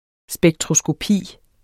Udtale [ sbεgtʁosgoˈpiˀ ]